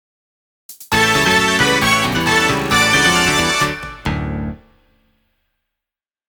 みじかめサウンドなシリーズです
ゲームサウンドやアイキャッチを意識した